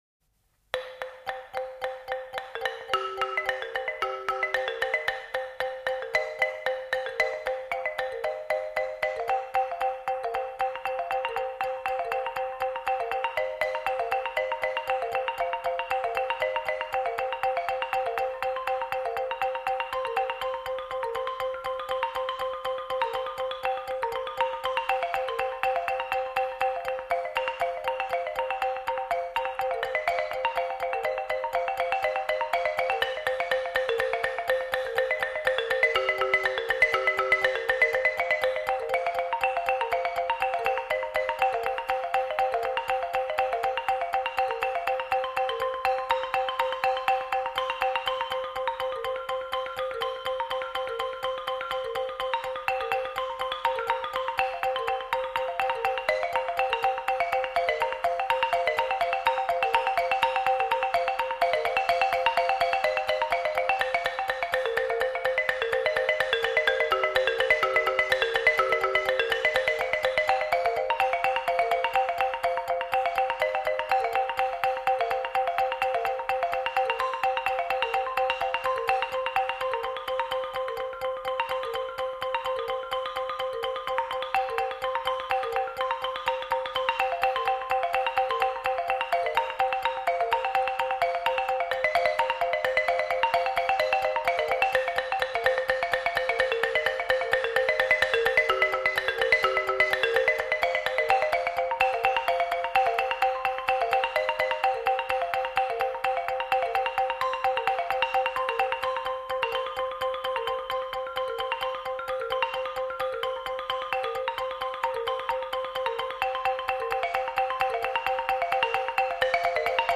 เดี่ยวโปงลางลายกาเต้นก้อน.mp3